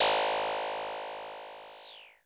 TECHNOISE  5.wav